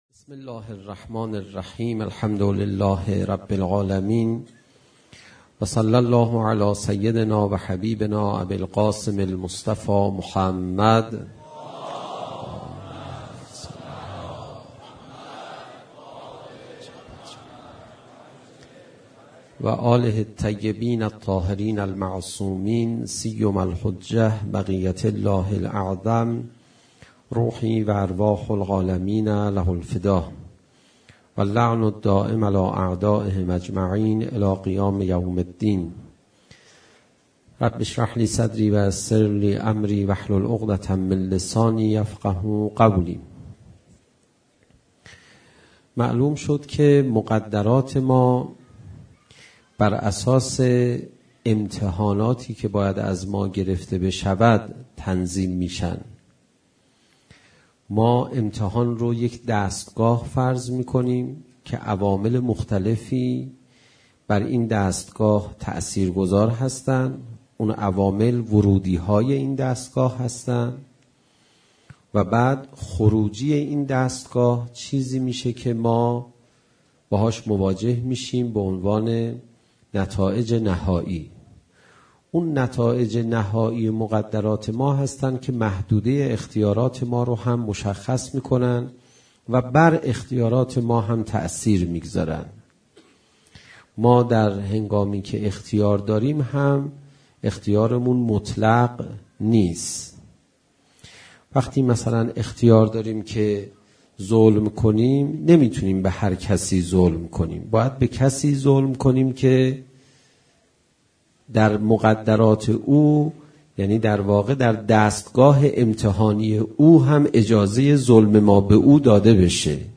گلچین سخنرانی های حجت الاسلام پناهیان
منتخب سخنرانی های حجت الاسلام پناهیان